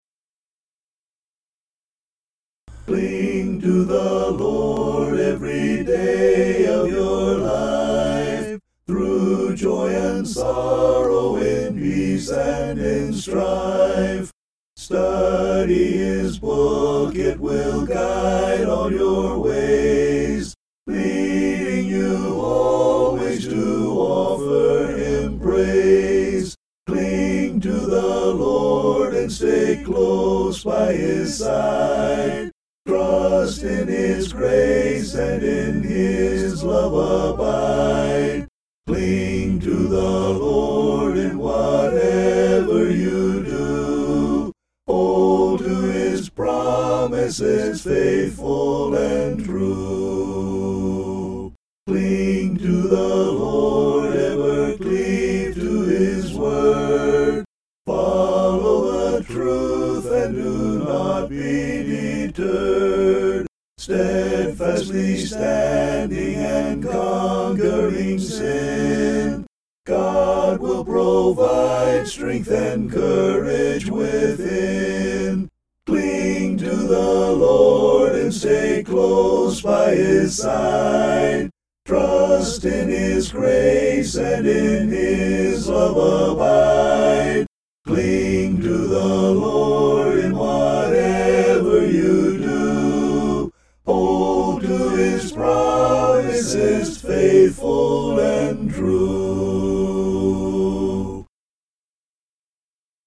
(An original hymn)
Tune: NEWKIRK (adaptation); Abraham Dow Merrill, 1845